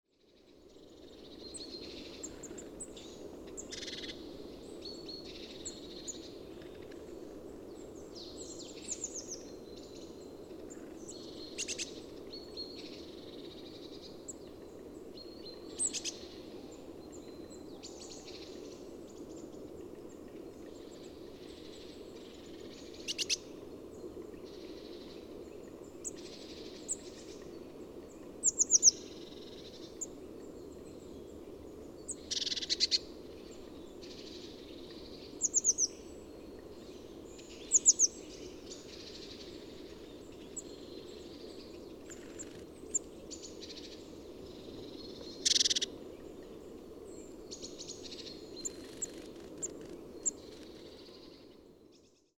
Eurasian Blue Tit Cyanistes c. caeruleus, social, excitement and flight calls